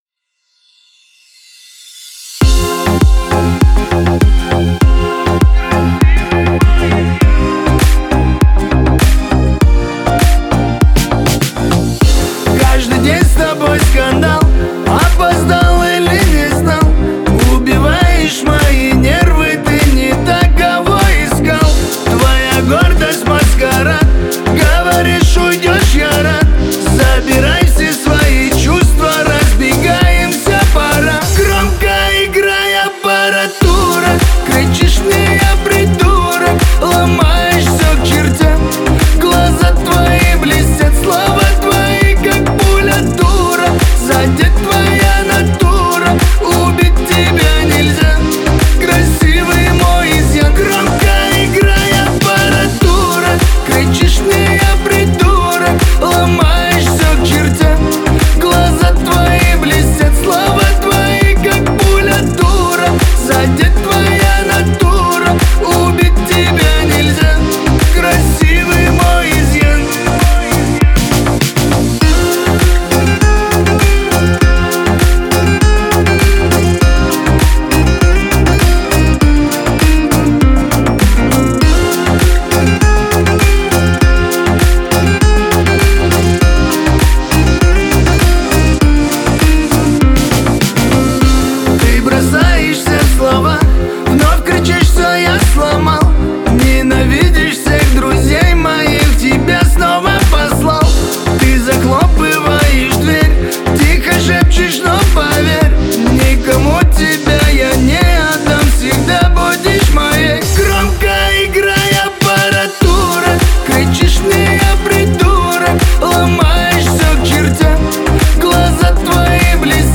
кавказские песни